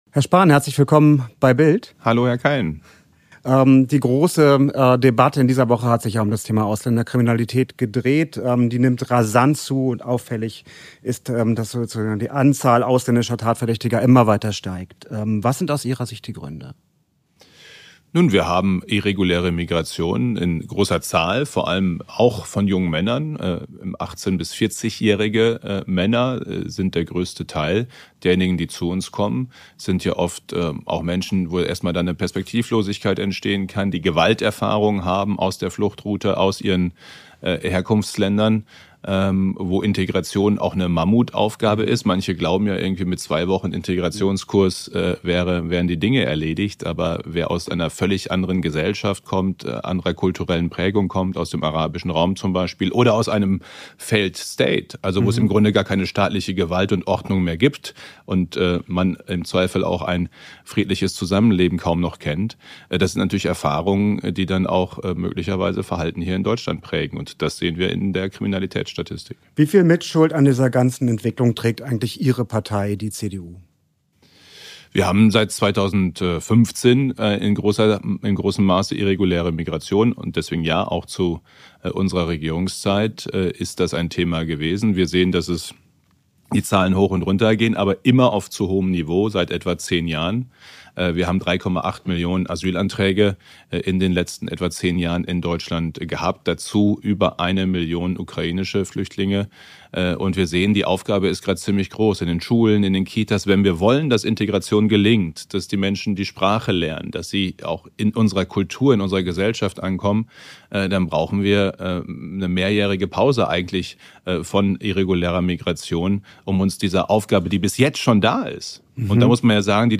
Unions-Fraktionsvize Jens Spahn (43, CDU) erscheint aufgeräumt und hochkonzentriert im BILD-Studio zum großen Talk.